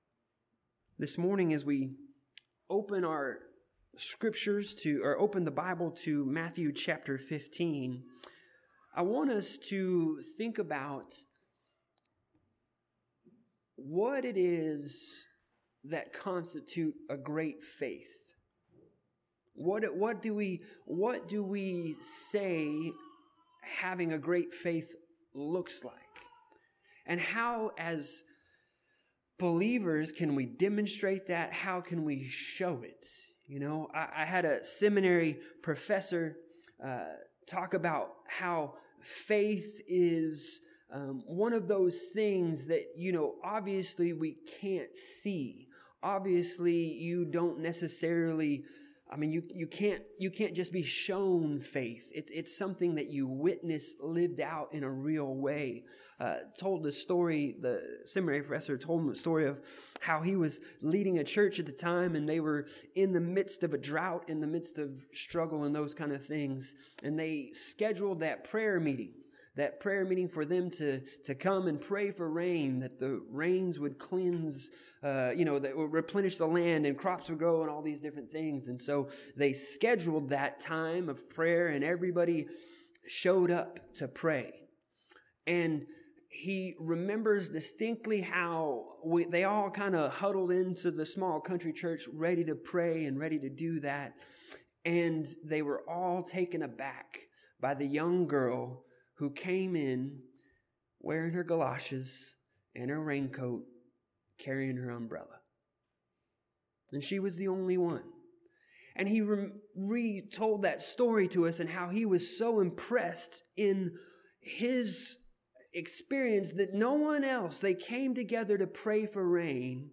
Passage: Matthew 15:21-28 Service Type: Sunday Morning